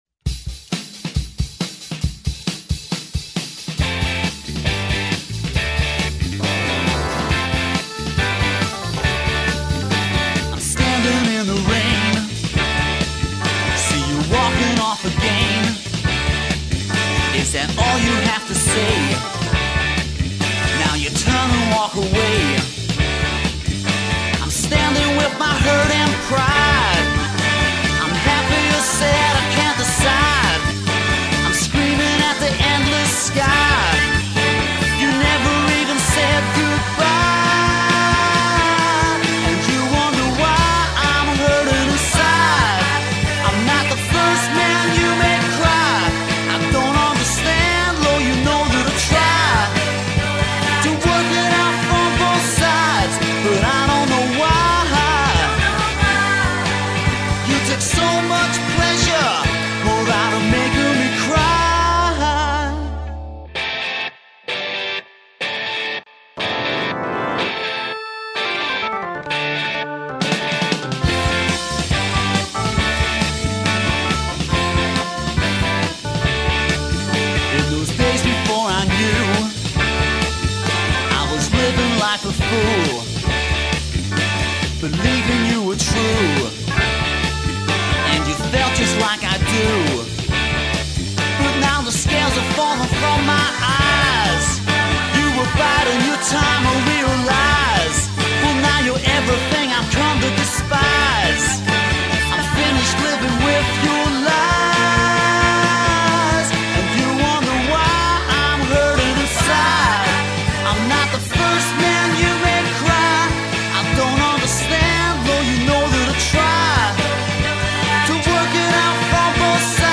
R&B mastery
So there you go. 18 blasts of prime ‘60s Mod Beat’.